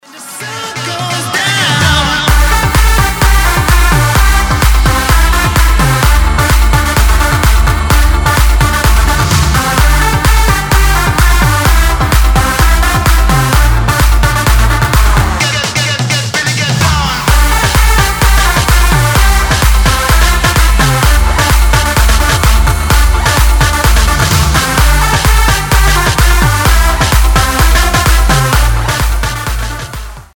• Качество: 256, Stereo
dance
club
progressive house
Festival Dance